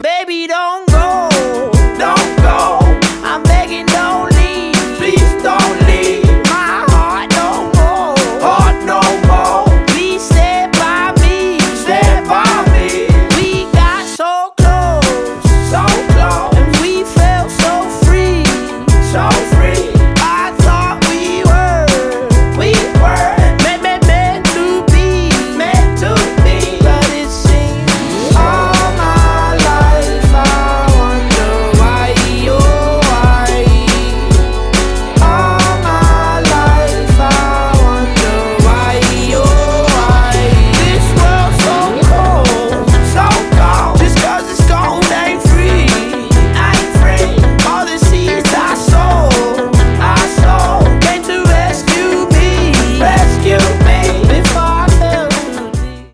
• Качество: 320, Stereo
поп
мужской голос